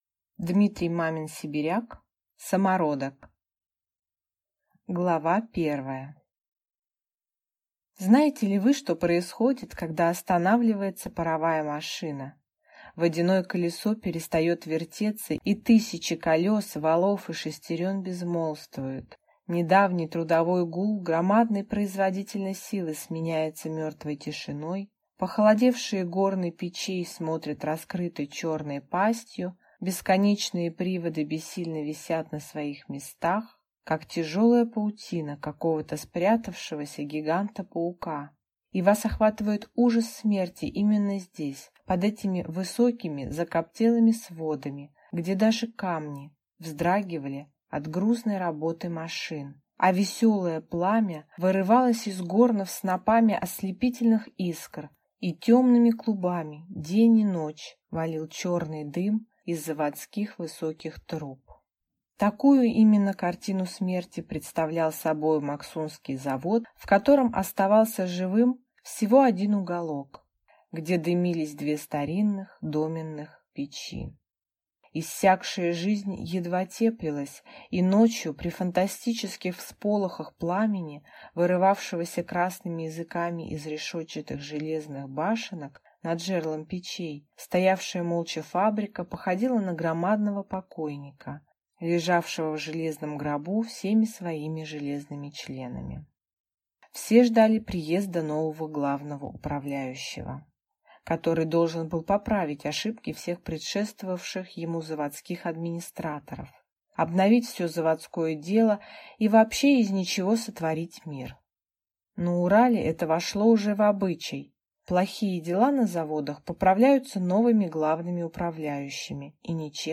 Аудиокнига Самородок | Библиотека аудиокниг